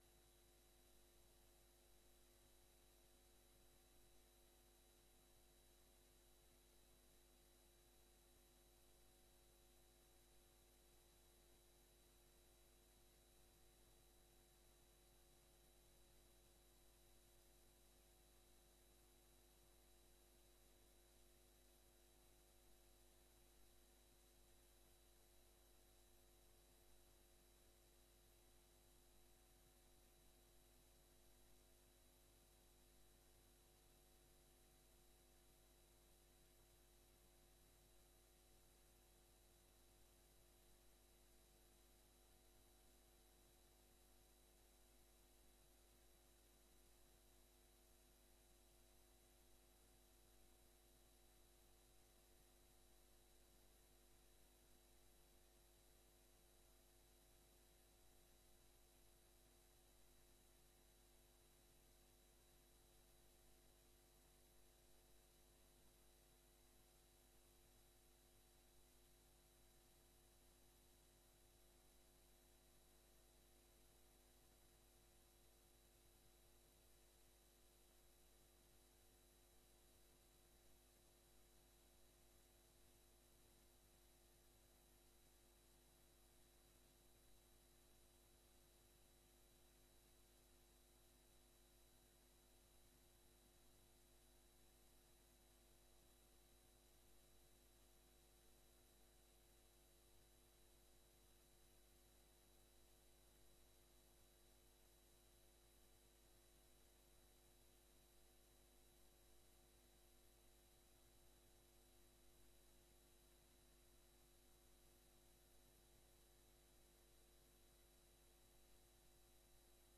Bijzondere raadsvergadering, aanvang 19.30 uur. Deze vergadering staat geheel in het teken van het afscheid van burgemeester Danny de Vries.